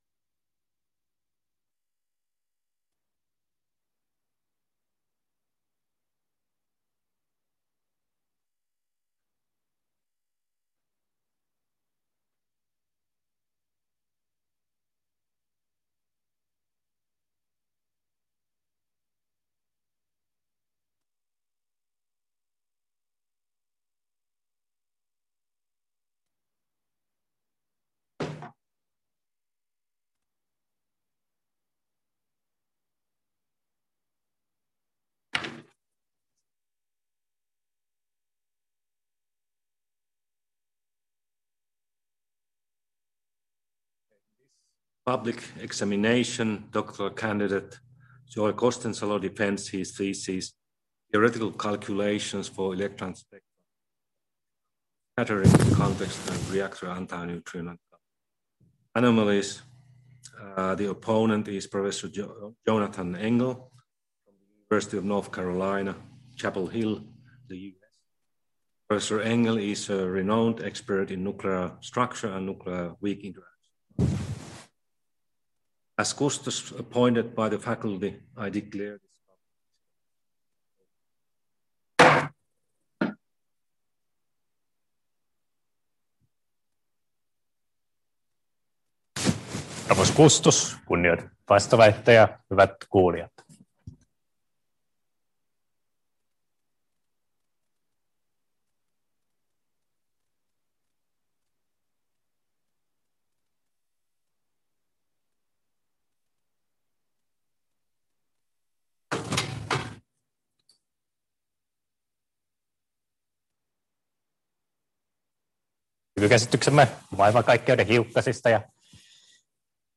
defends his doctoral dissertation in Physics